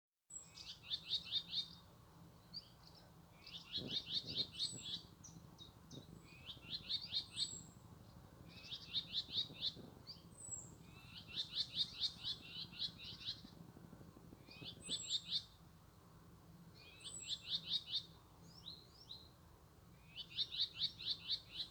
Birds -> Warblers ->
Whitethroat, Curruca communis
StatusAgitated behaviour or anxiety calls from adults